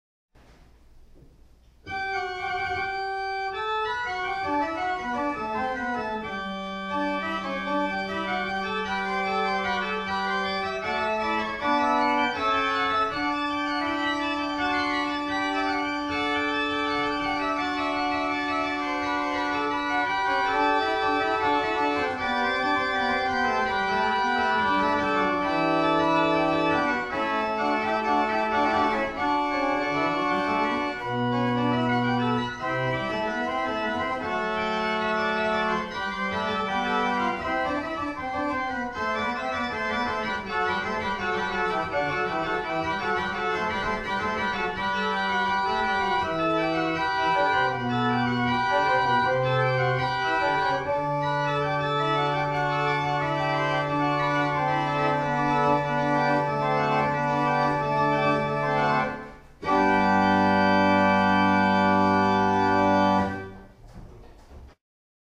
1802 Tannenberg Organ
Hebron Lutheran Church - Madison, VA